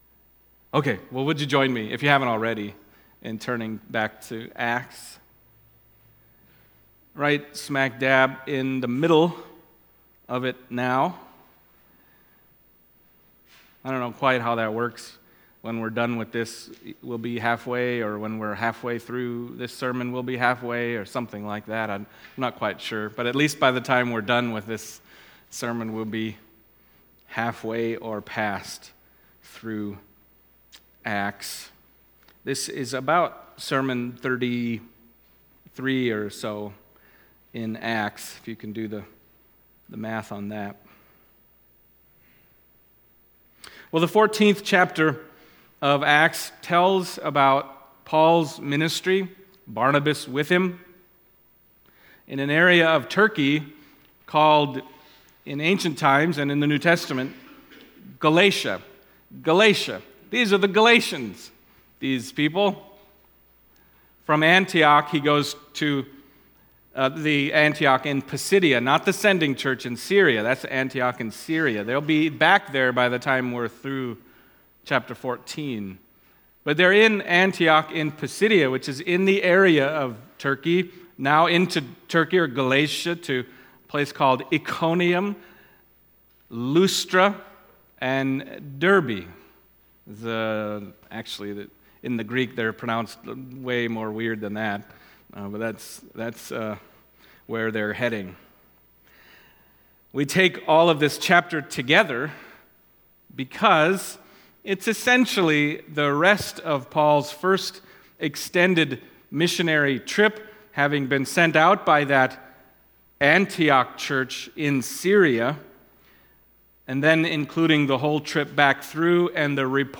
Acts Passage: Acts 14:1-28 Service Type: Sunday Morning Acts 14:1-28 « What Is the Lord’s Supper?